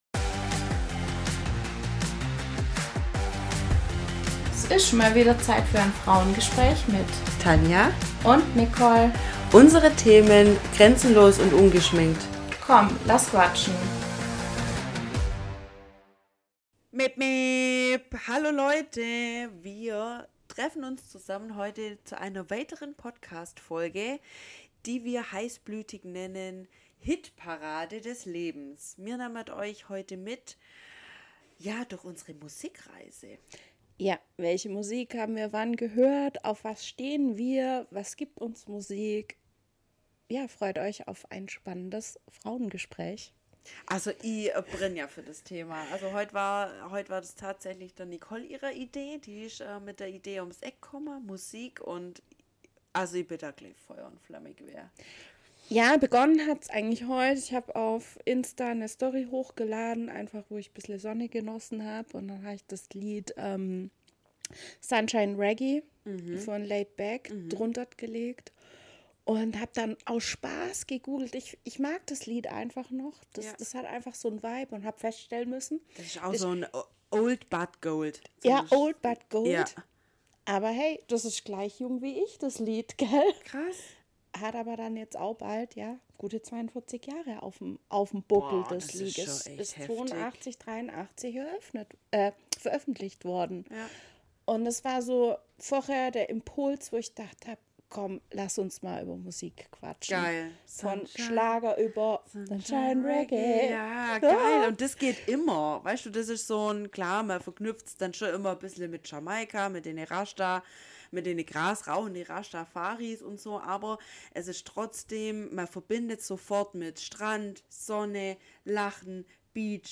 Welche Musikrichtung hat Euch am meisten geprägt? Das alles haben wir Euch in eine Stunde Frauengespräch gepackt und dabei haben wir doch glatt in eigenen musikalischen Erinnerungen geschwelgt.